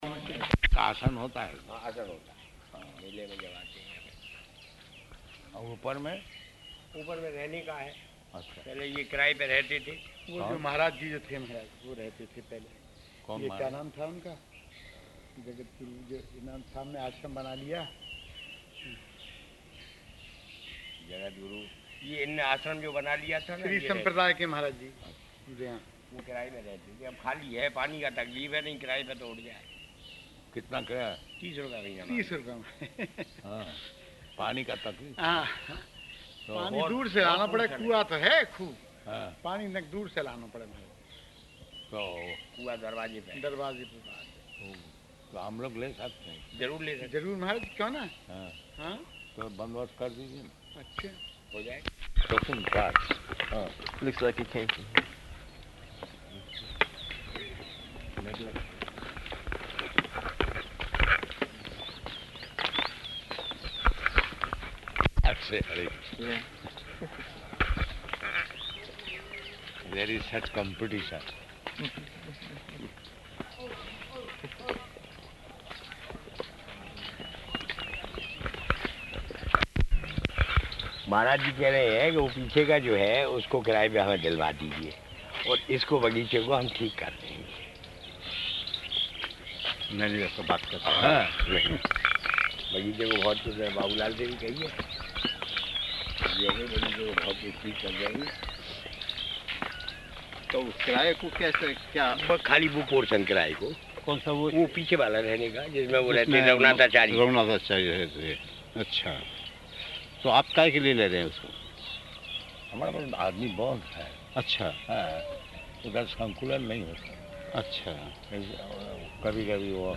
Morning Walk in Raṅga Gardens
Morning Walk in Raṅga Gardens --:-- --:-- Type: Walk Dated: April 7th 1976 Location: Vṛndāvana Audio file: 760407MW.VRN.mp3 Prabhupāda: [Hindi with Indians] [break] ...dozen carts.